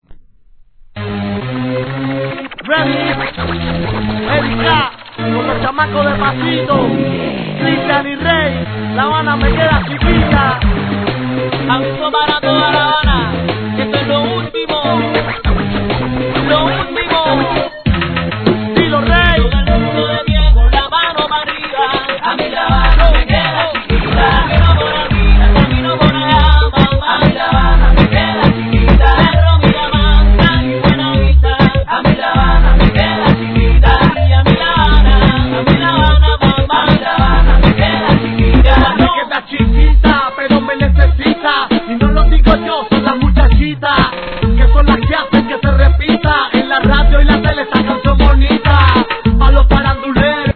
1. HIP HOP/R&B
■REGGAETON